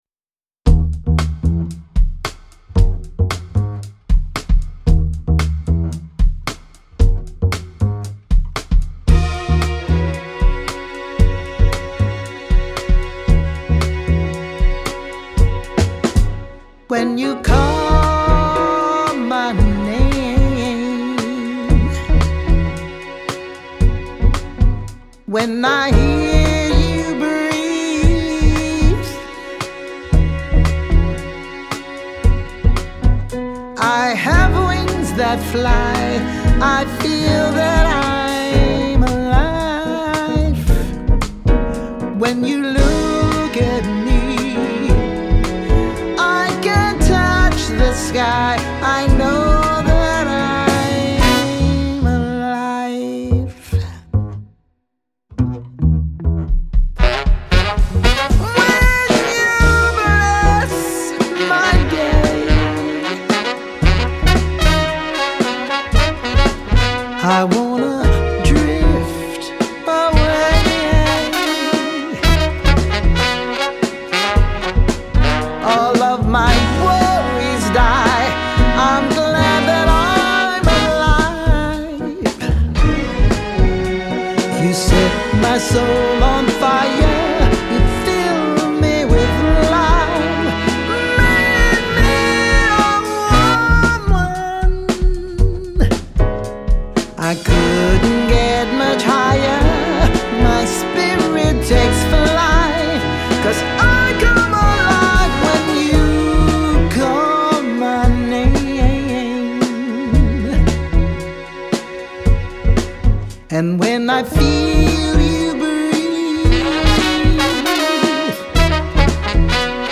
vocals
piano
bass
guitar
drums
saxophone
trumpet
trombone
violin
cello